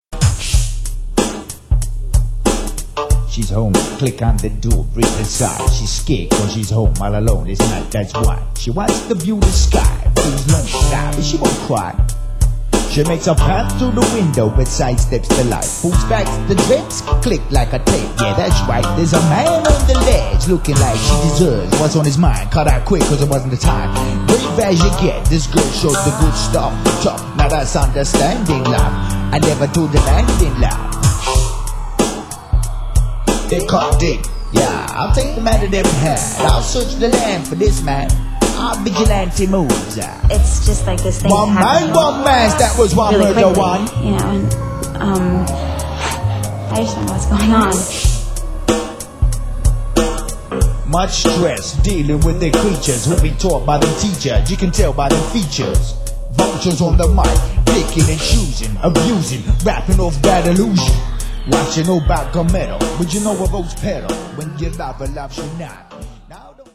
Gangsta - Dope